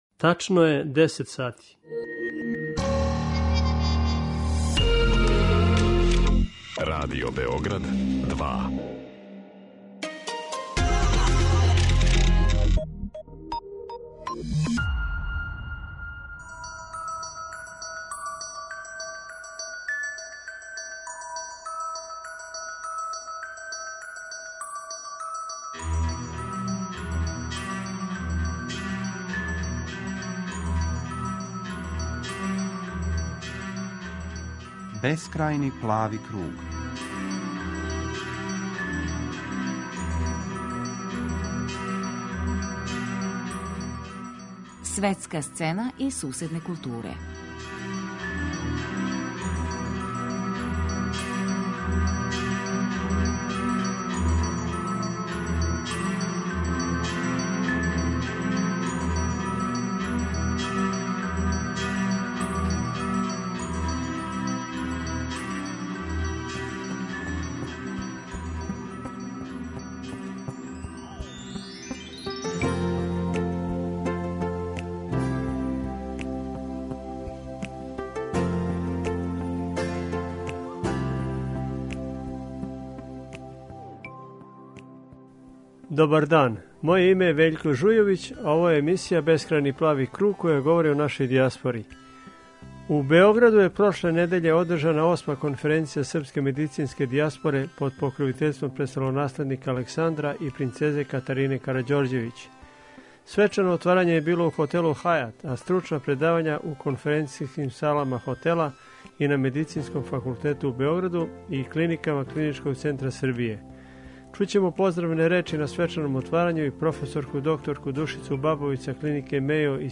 Емисија о дијаспори